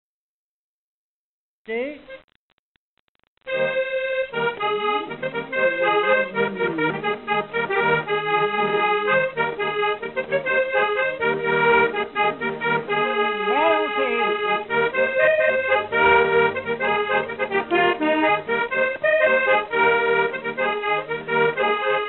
Pas d'été Détails de l'archive Titre Pas d'été Origine du titre : interprète Note 5ème figure du quadrille.
Résumé instrumental
danse : quadrille : pas d'été
Pièce musicale inédite